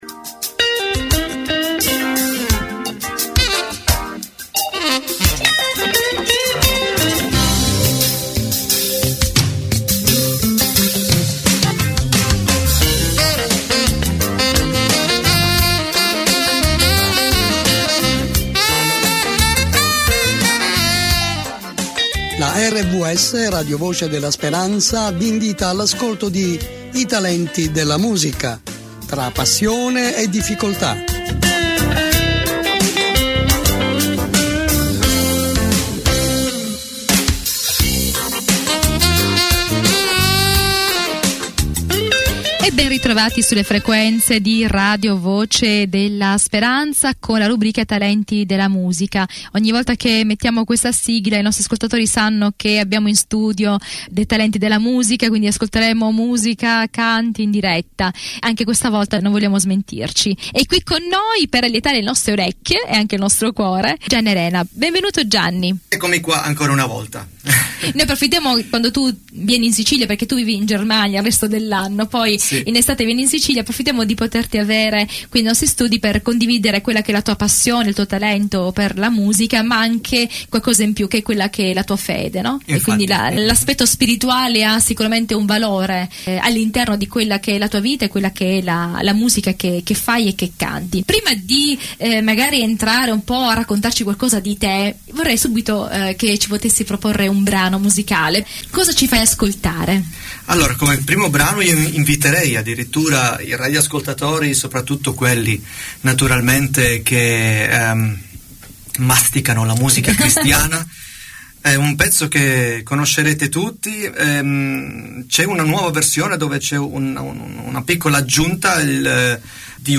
In questa puntata attraverso brani cantati e suonati dal vivo